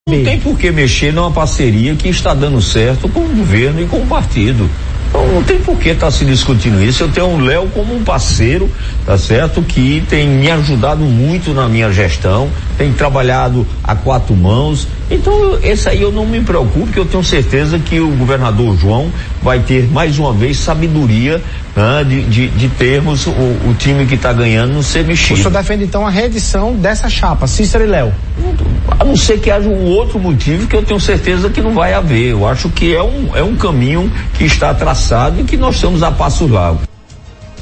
As declarações de Cícero repercutiram em entrevista ao programa Frente a Frente da TV Arapuan.